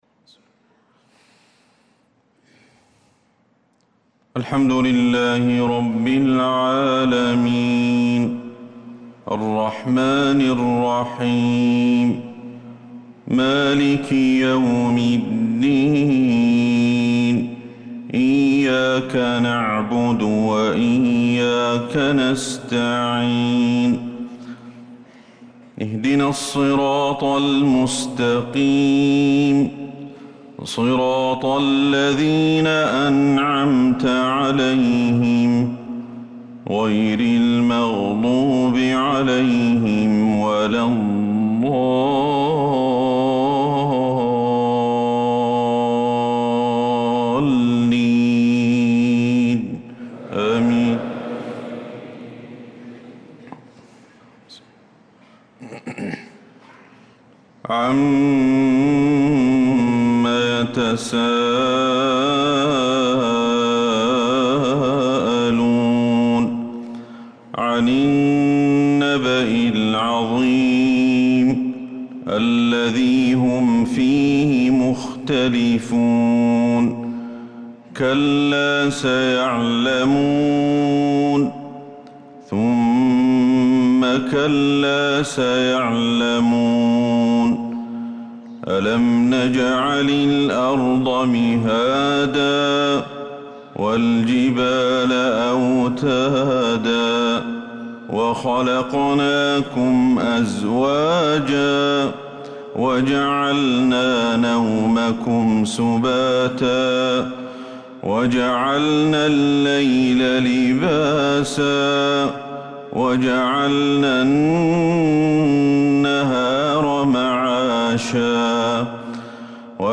صلاة الفجر